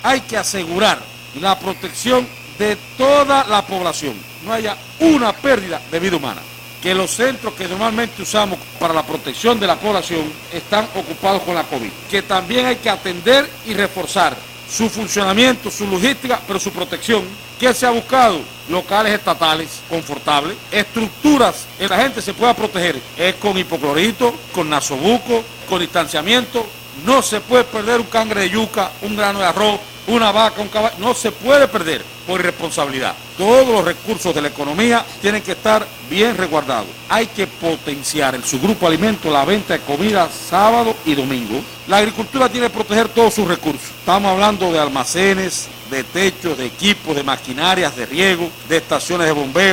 Declaraciones-de-Federico-Hernández-presidente-del-CDP-en-Granma.mp3